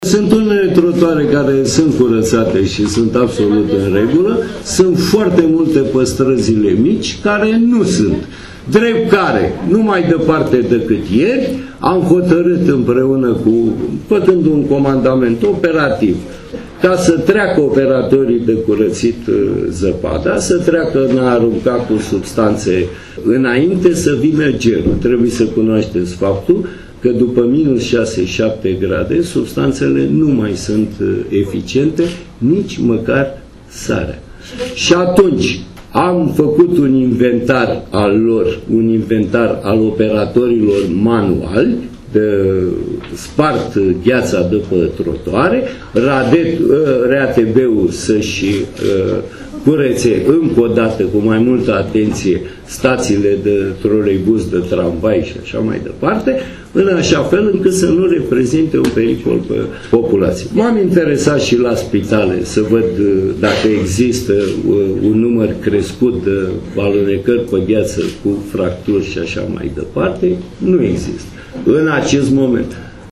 Oprescu a spus că a dispus ca operatorii de dezăpezire să treacă la îndepărtarea manuală a gheții și la împrăştierea de material antiderapant, înainte de următoarea perioada geroasă: